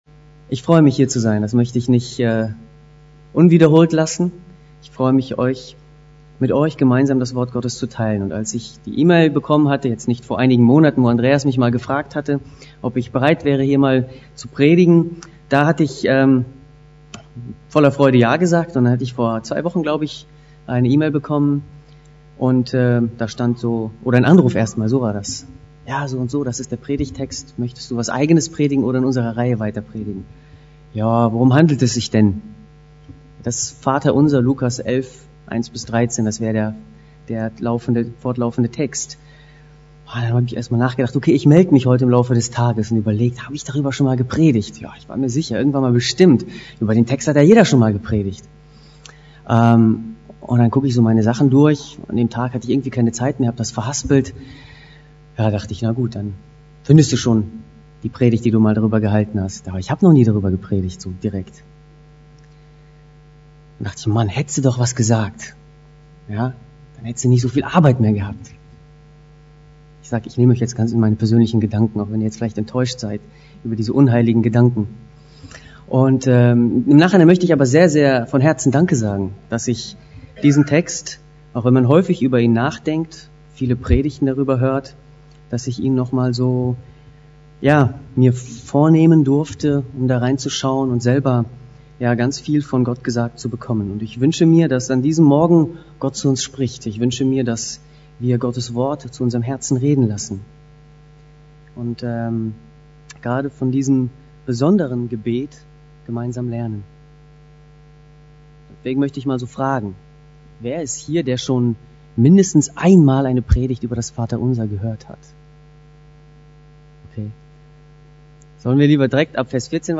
Einzelpredigten